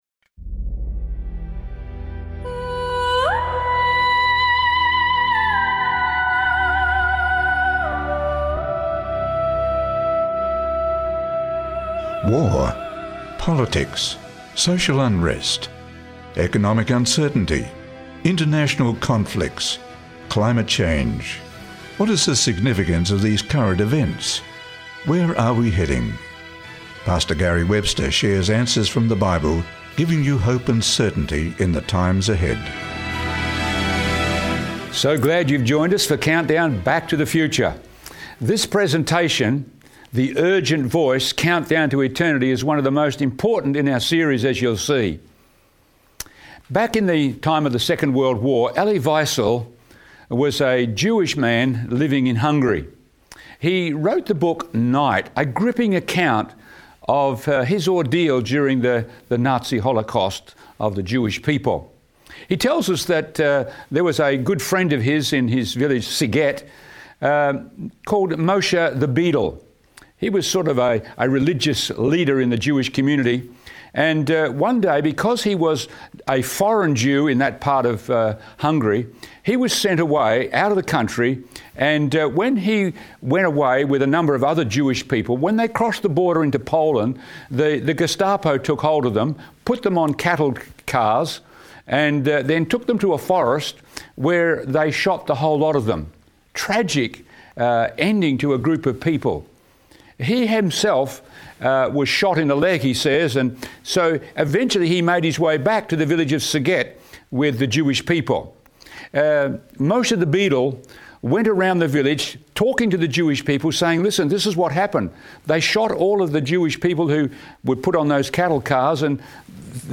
Investigate the urgent prophetic countdown to the end times in this captivating presentation.